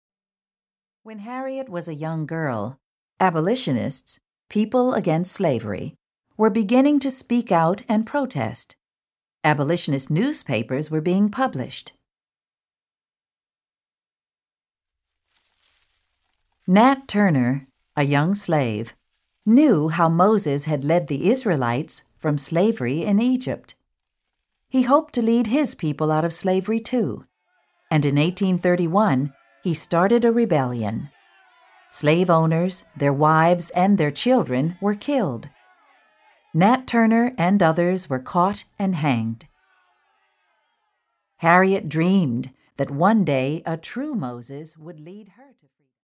When the famed Regent Diamond is stolen, Julieta is in the middle of a high stakes mystery. With a bilingual family and international travel, the full cast portrays a range of accents, as well as phrases in Spanish and French, with panache.